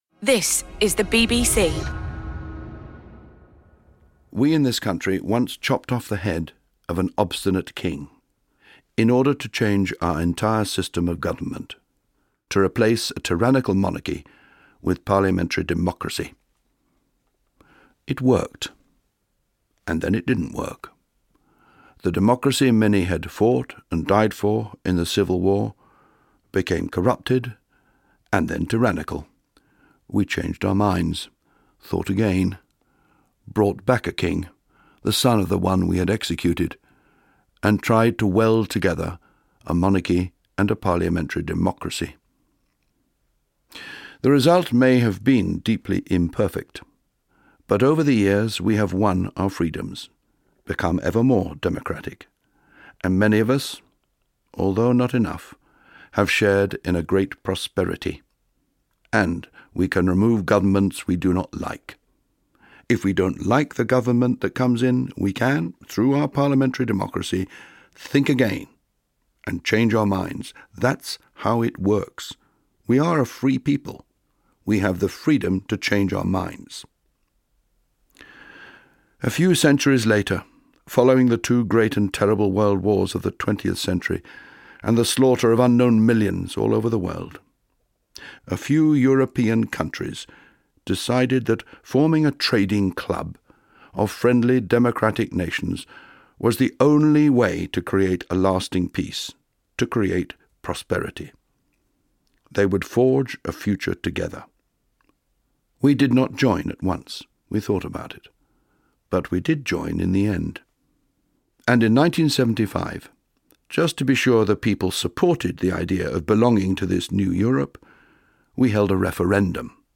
A couple of weeks ago I was listening to Radio 4 and heard this Point of View by War Horse author Michael Morpurgo.